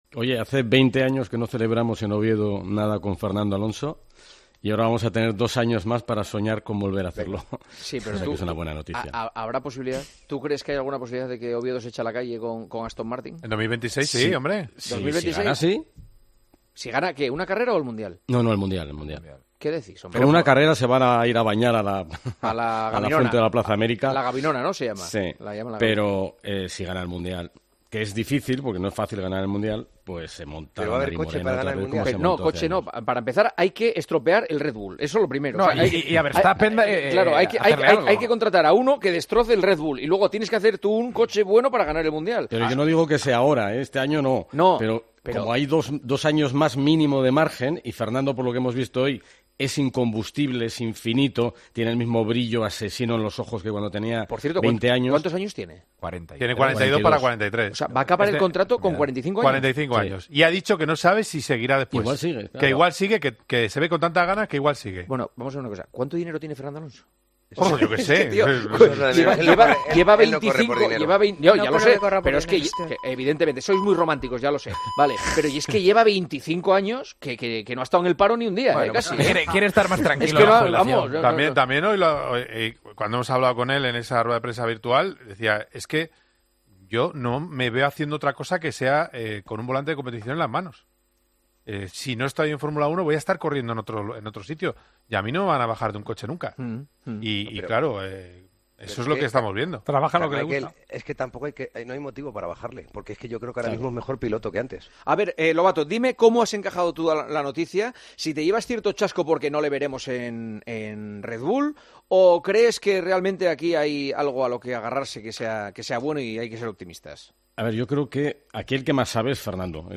Santi Cañizares y el director de El Partidazo de COPE seguían planteando cuestiones sobre el piloto español y Lobato explicó, finalmente, el motivo de la elección.